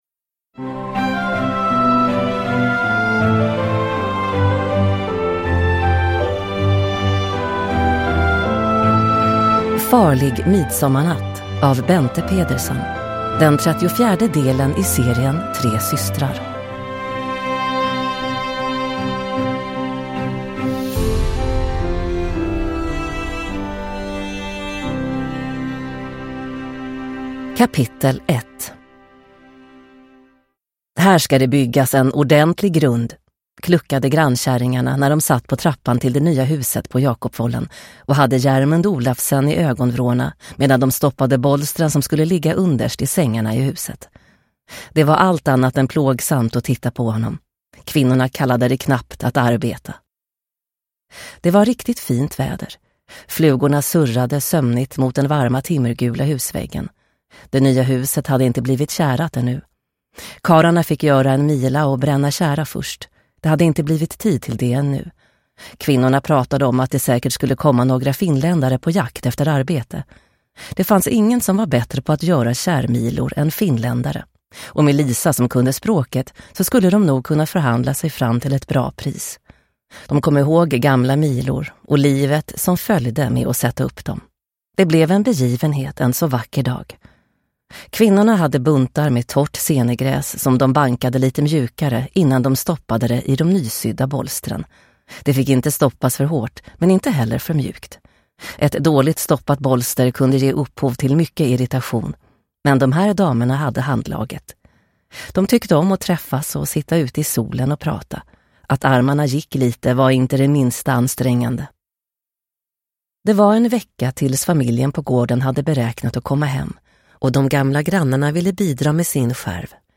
Farlig midsommarnatt – Ljudbok – Laddas ner